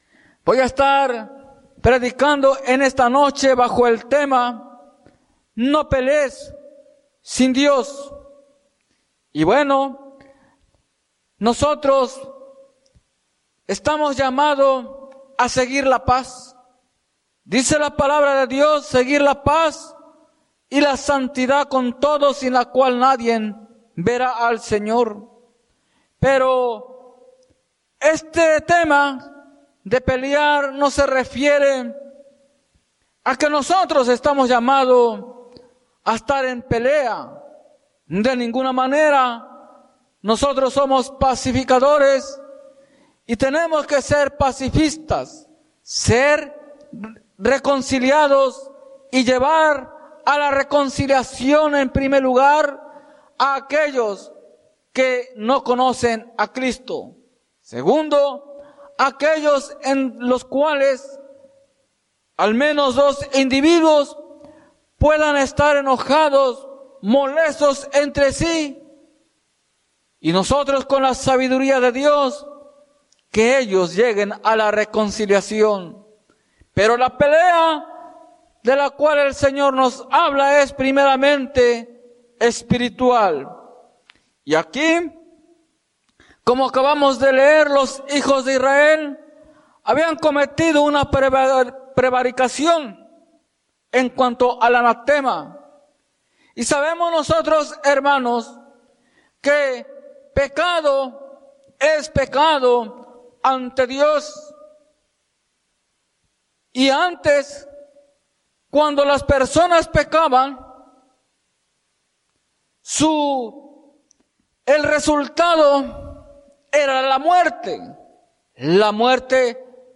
Iglesia Misión Evangélica
Predica